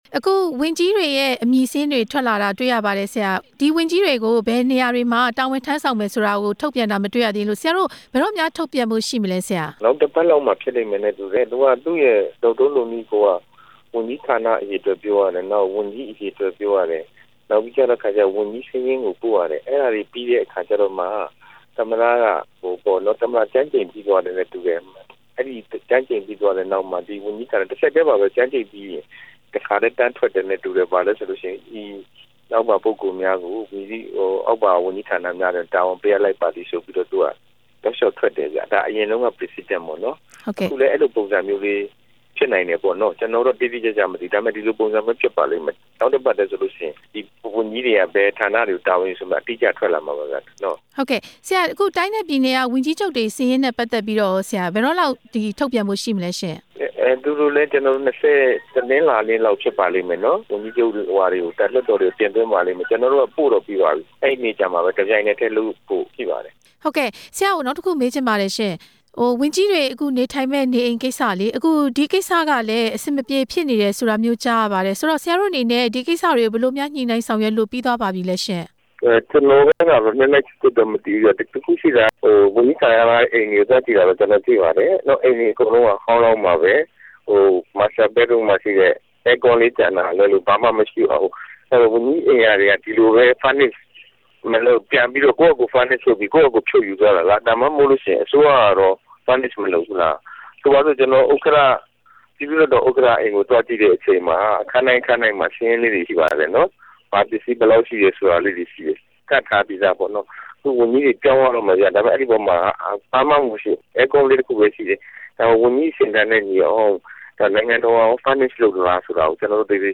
ဝန်ကြီးချုပ်အမည်စာရင်းထုတ်ပြန်ရေး ဒေါက်တာဇော်မြင့်မောင်နဲ့ မေးမြန်းချက်